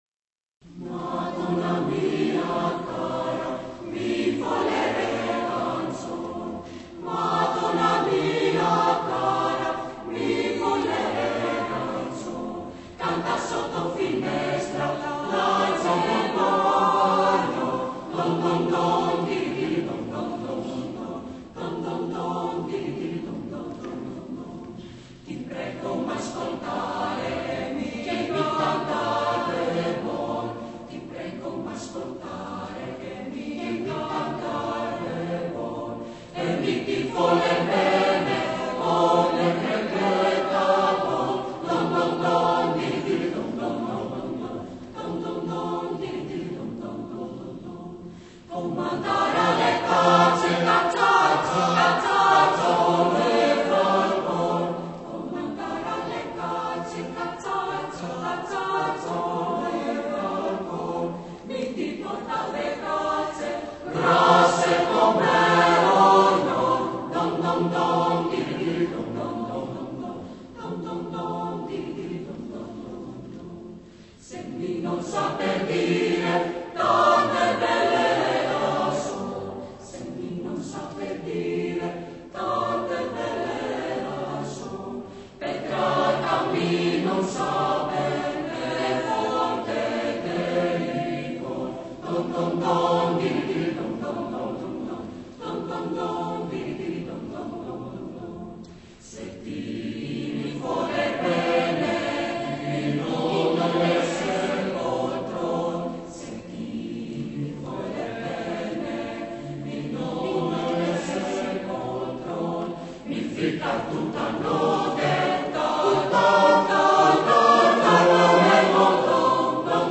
Renaissance